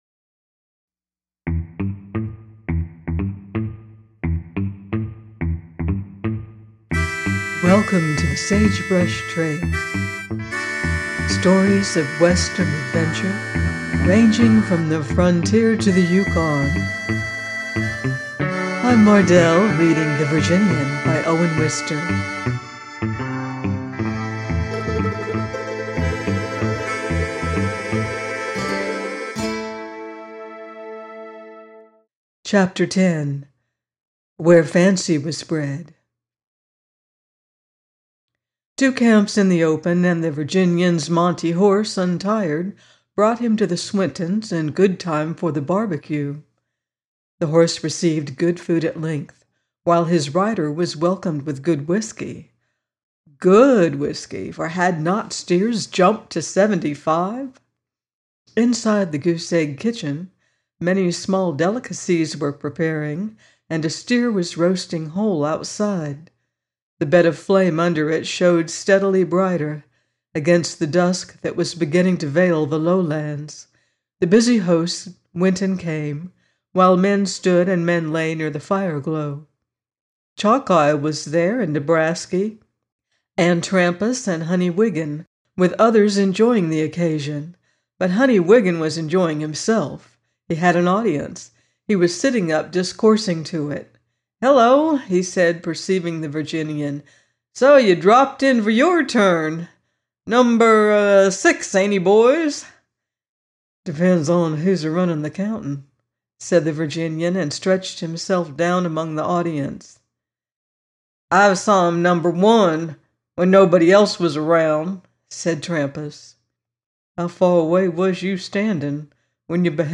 The Virginian - by Owen Wister - audiobook